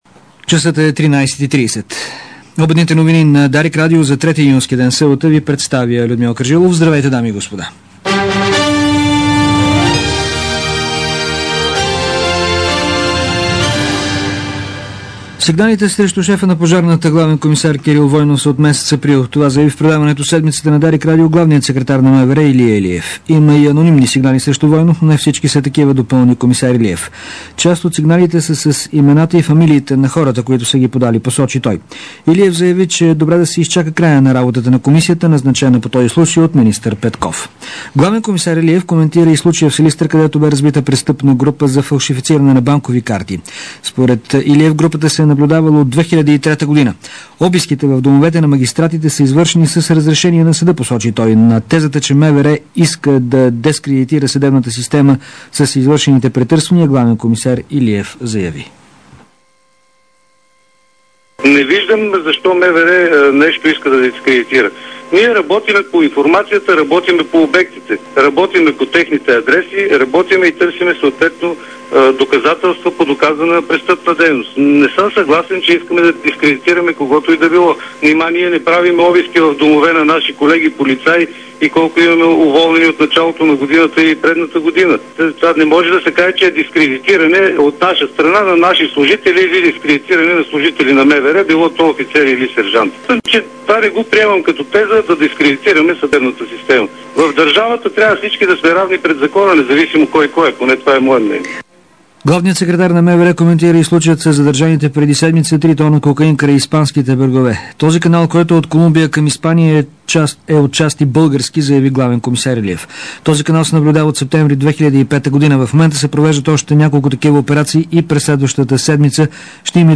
DarikNews audio: Обедна информационна емисия 03.06.2006